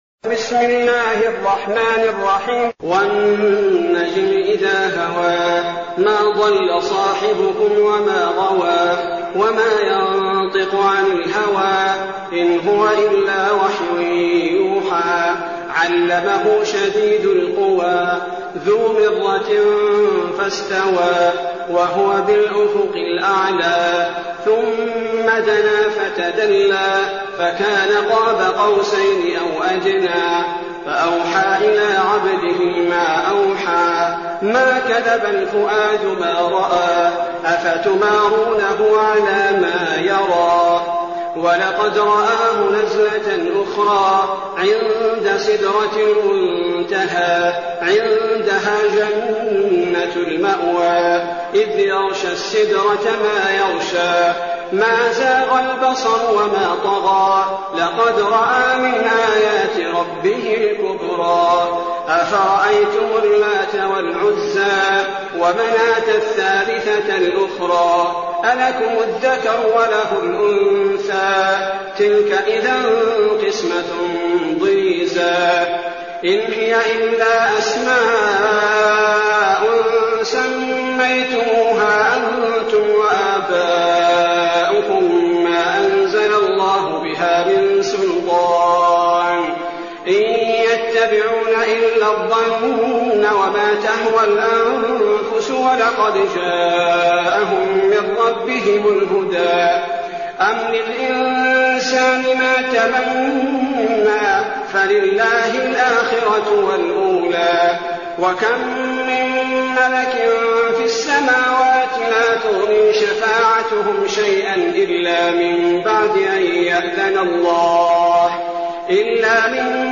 المكان: المسجد النبوي الشيخ: فضيلة الشيخ عبدالباري الثبيتي فضيلة الشيخ عبدالباري الثبيتي النجم